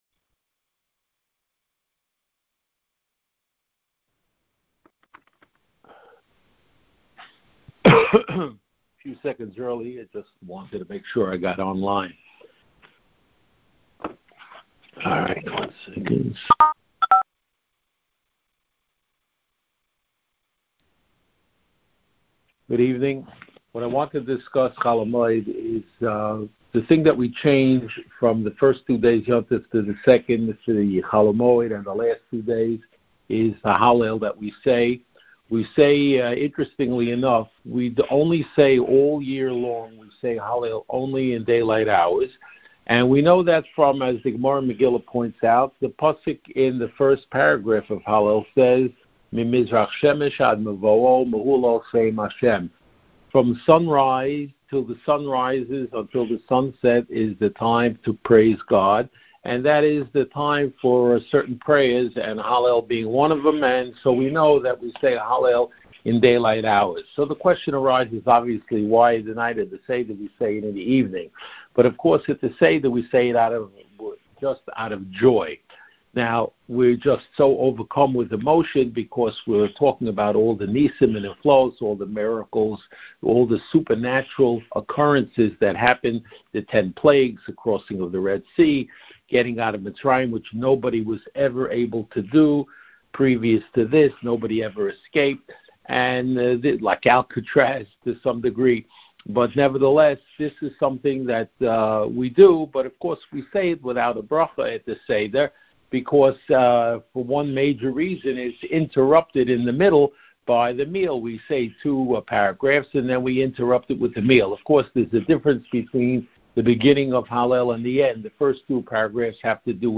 Chol Hamoed shiur